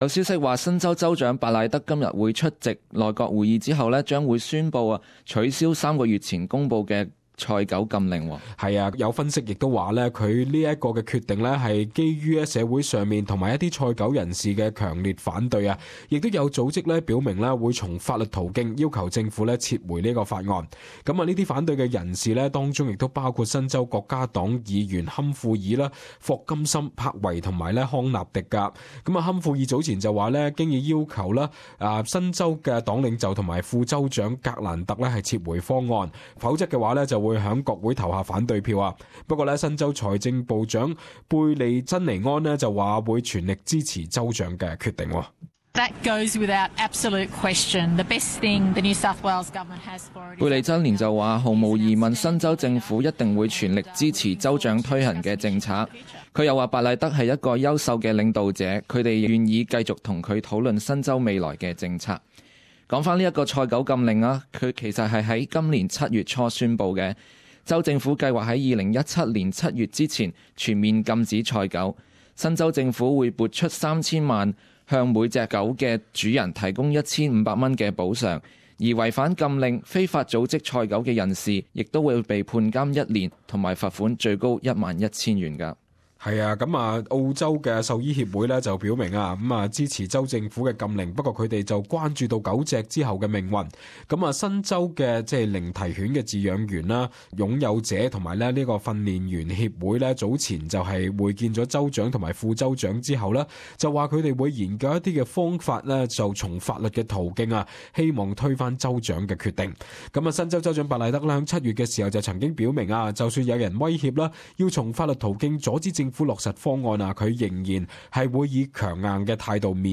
【時事報導】州長白賴德或宣布取消賽狗禁令